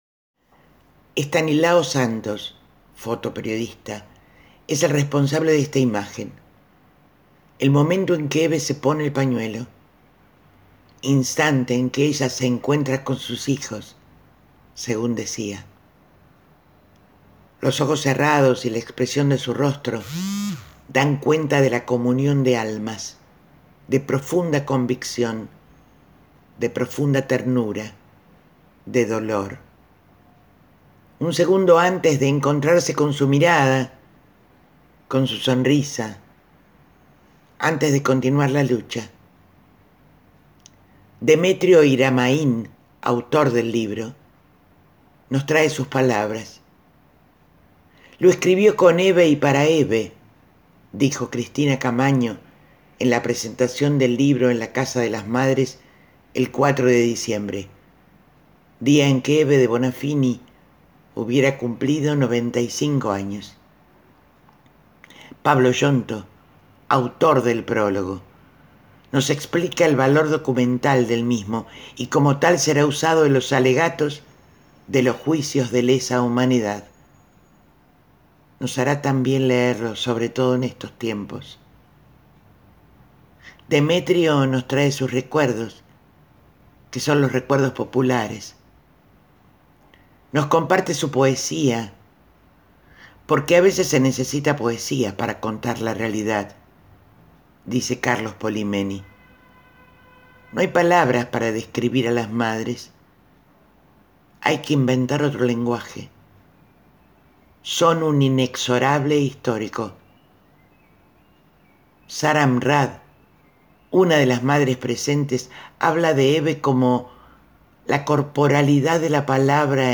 Ella convoca al público presente a golpearse levemente el pecho y ella canta a capela al compás de ese sentimiento sonoro.